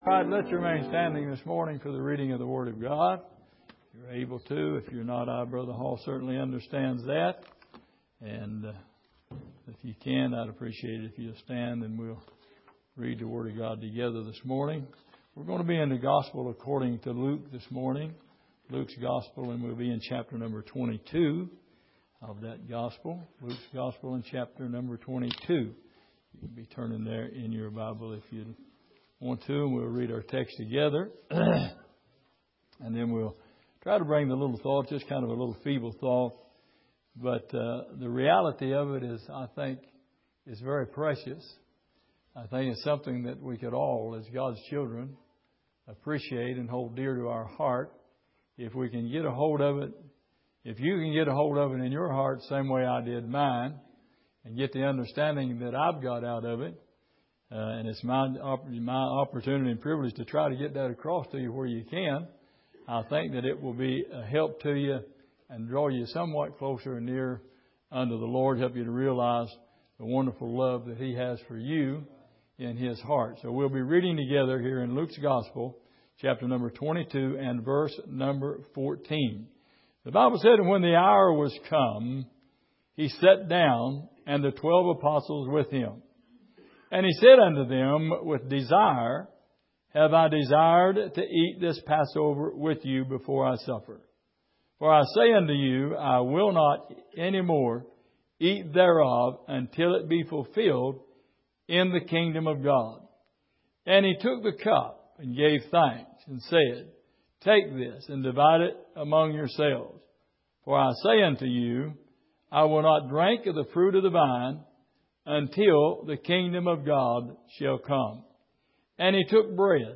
Miscellaneous Passage: Luke 22:14-20 Service: Sunday Morning The Fading Passover « Christ Working In His Church Is Your Burden Too Heavy To Carry?